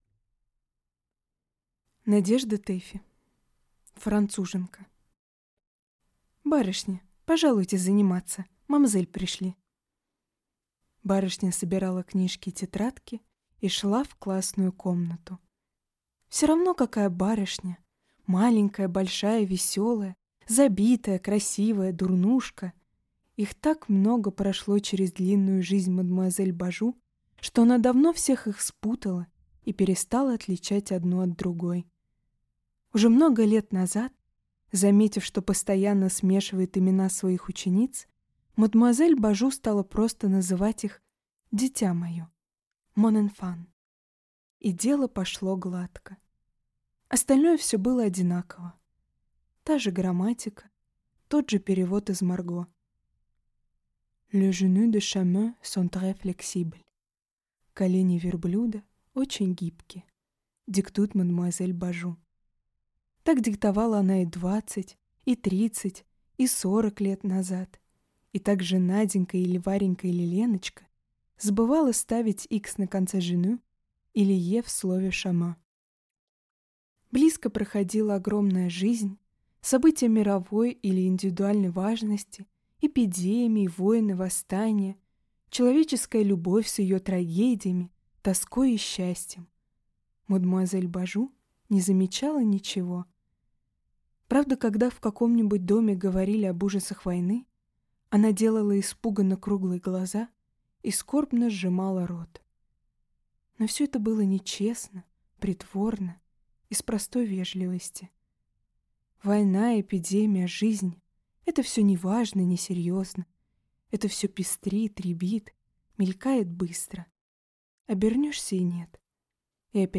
Аудиокнига Француженка | Библиотека аудиокниг